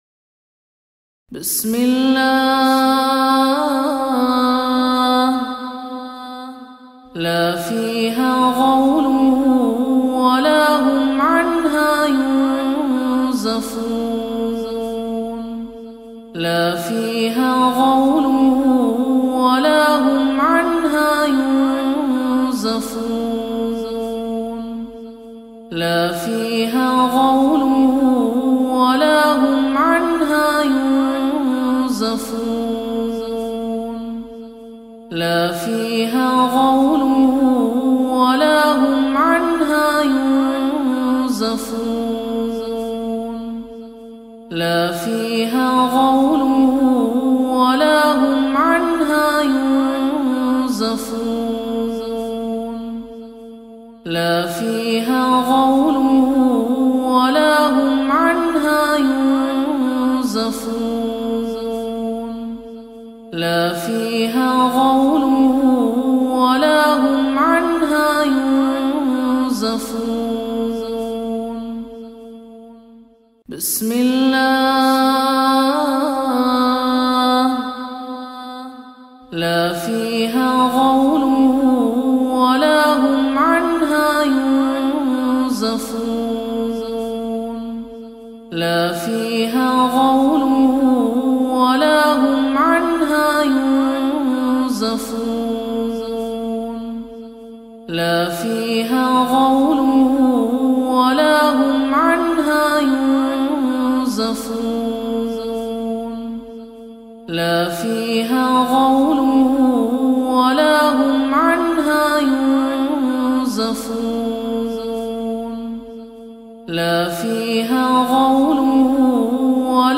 Ruqyah FOR Period Pain – পিরিয়ডকালীন ব্যাথার জন্য রুকইয়াহ